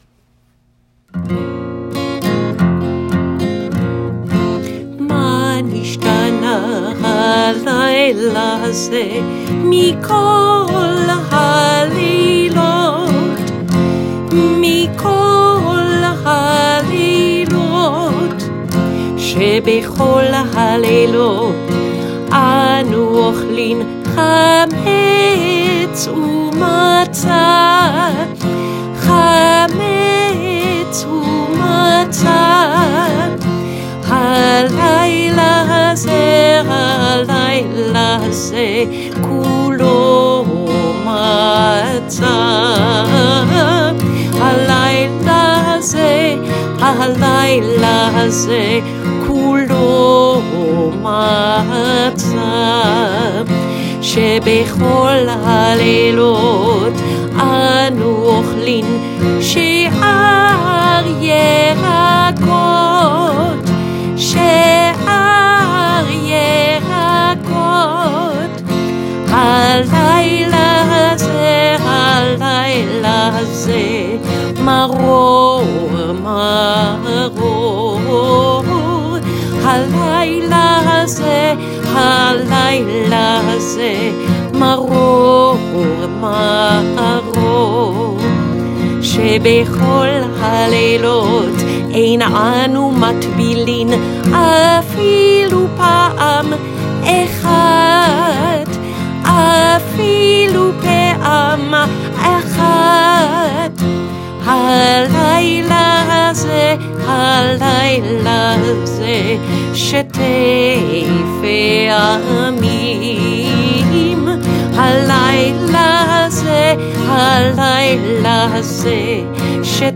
1-ma-nishtana-children-pg-21-a-family-haggadah.m4a